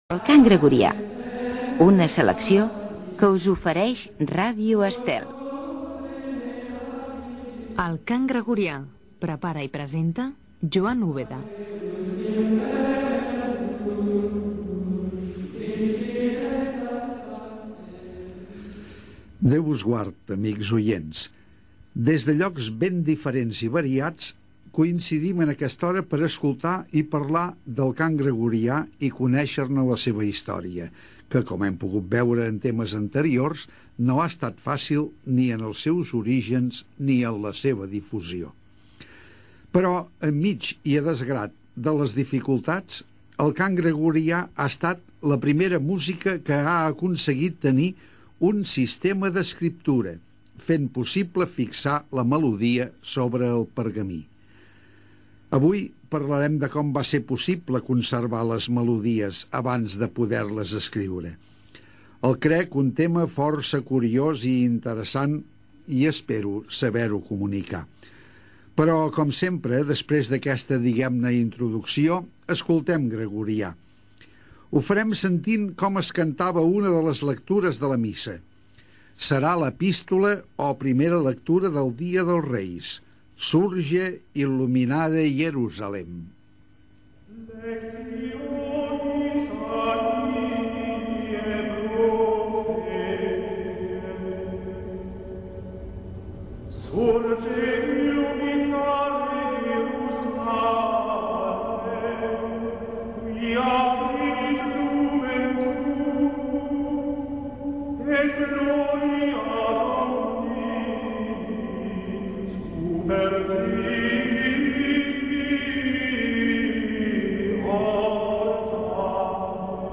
Careta del programa, presentació, característiques del Cant Gregorià, cant de la primera lectura del Dia de Reis
Gènere radiofònic Musical